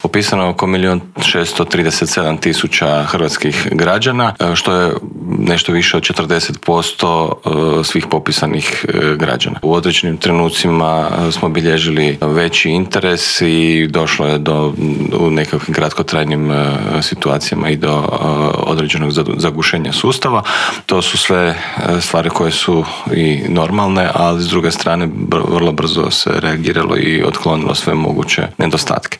ZAGREB - U Intervjuu tjedna Media servisa gostovao je državni tajnik u Središnjem državnom uredu za razvoj digitalnog društva Bernard Gršić koji nam je otkrio kako je proteklo prvo online samopopisivanje građana, koliko ljudi koristi sustav e-Građani, što sve taj sustav nudi, što planira ponuditi kao i koje su njegove prednosti.